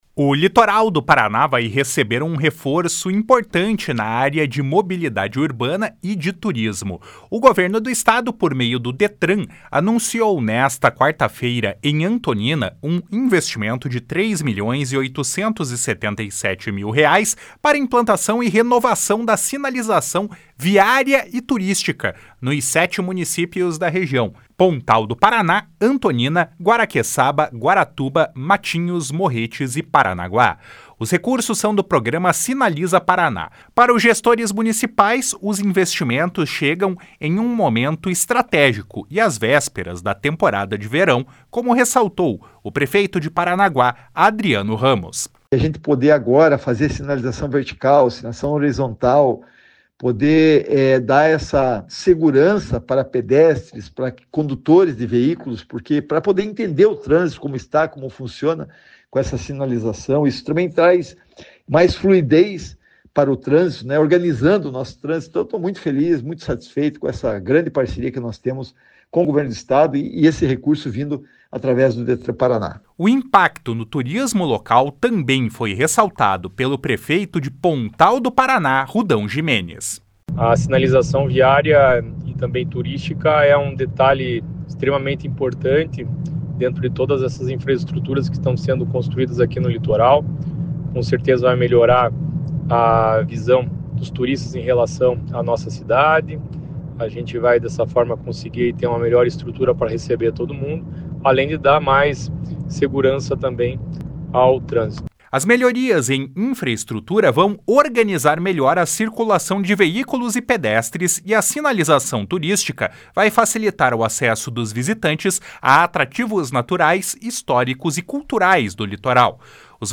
Para os gestores municipais, os investimentos chegam em um momento estratégico e às vésperas da temporada de verão, como ressaltou o prefeito de Paranaguá, Adriano Ramos.
O impacto no turismo local também foi ressaltado pelo prefeito de Pontal do Paraná, Rudão Gimenes.